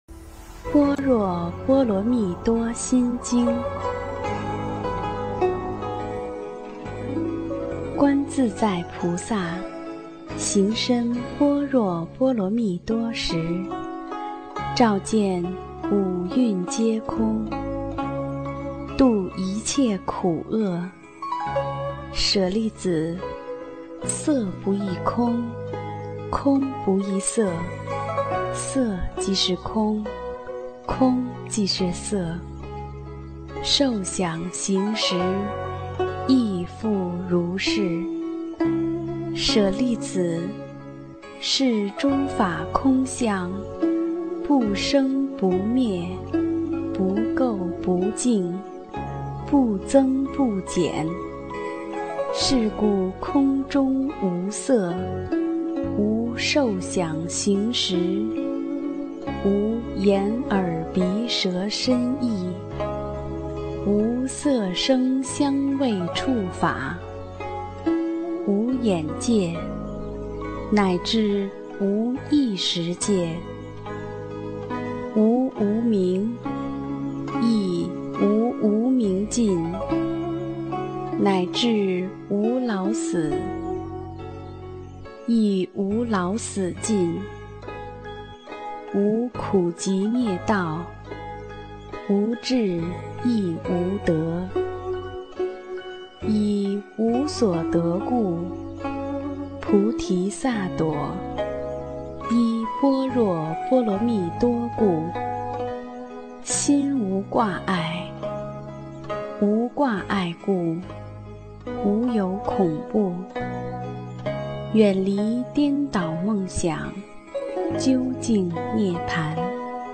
音频：经文教念-《心经》